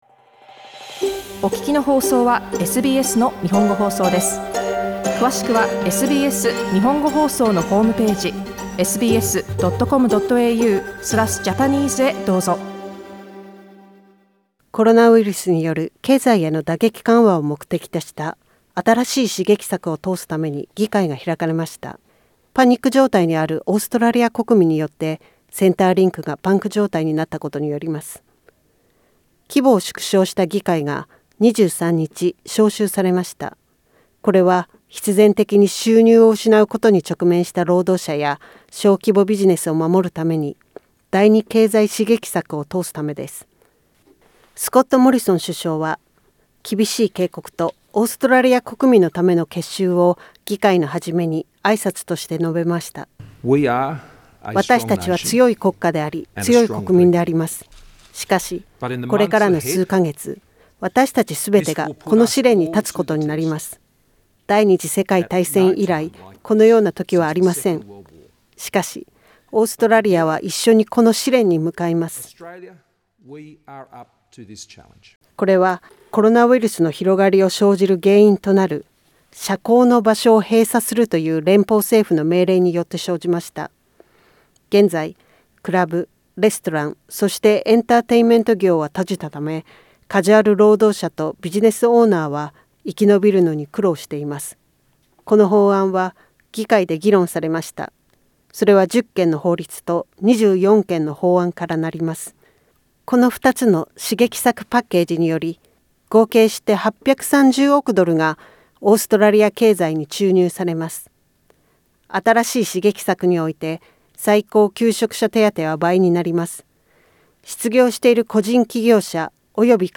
モリソン政権が発表した支援策第２弾についてのリポートです。